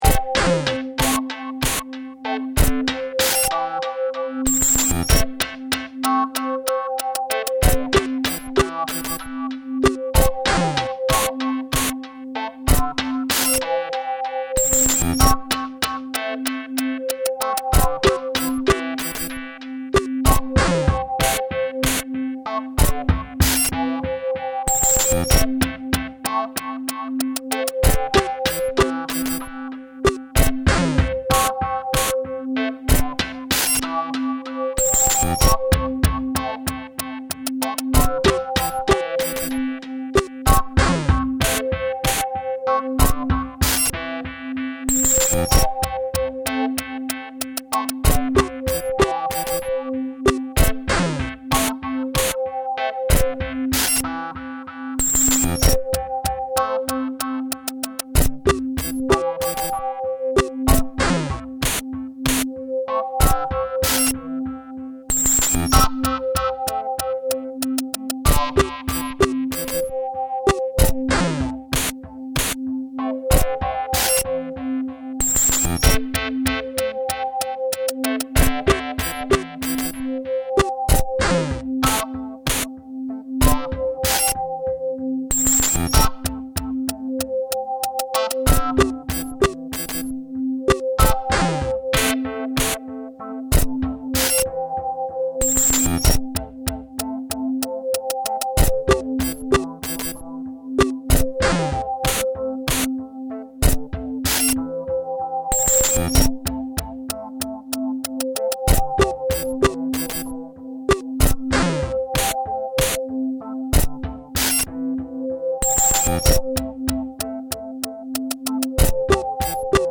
laptop jamming project